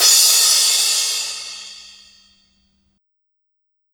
Bp Crash.wav